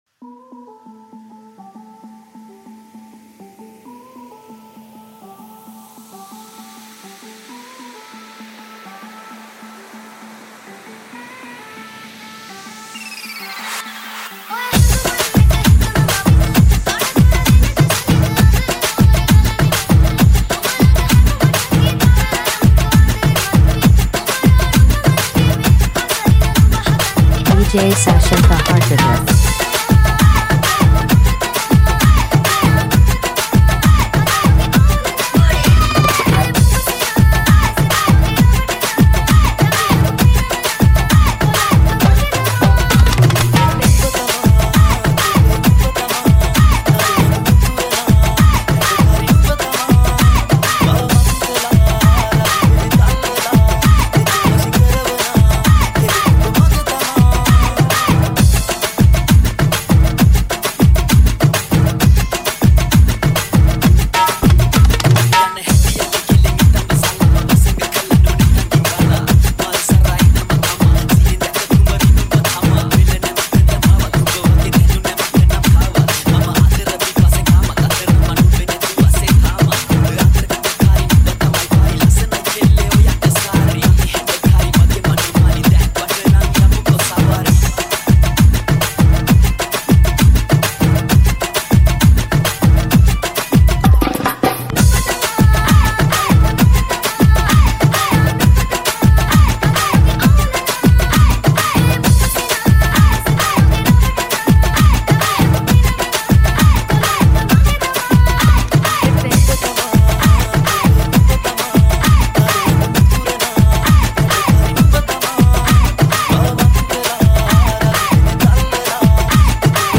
High quality Sri Lankan remix MP3 (2.8).
high quality remix